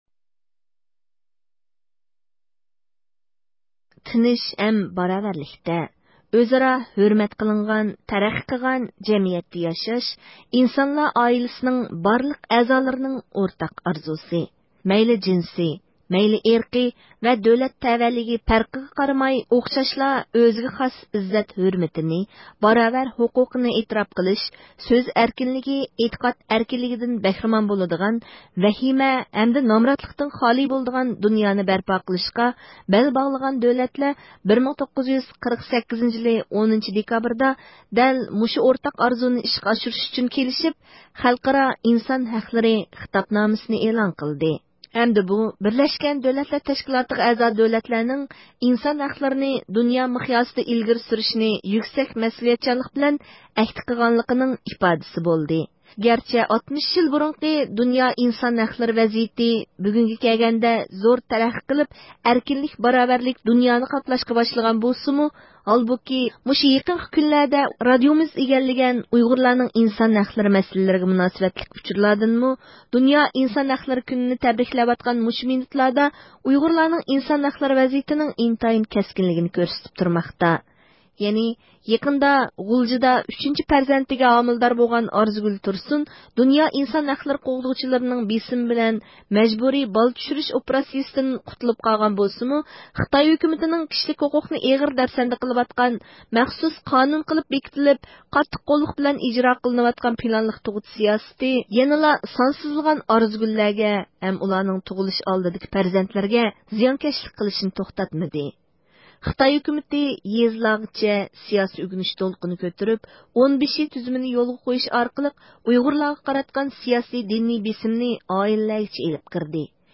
بىز خەلقئارا ئىنسان ھەقلىرى كۈنىنىڭ 60 يىللىقى مۇناسىۋىتى بىلەن ئۇيغۇر مىللىي ھەرىكىتى رەھبىرى رابىيە قادىر خانىمنى زىيارەت قىلىپ، ئۇيغۇرلارنىڭ نۆۋەتتىكى ئىنسان ھەقلىرى ۋەزىيىتى ھەققىدە سۆھبەتتە بولدۇق.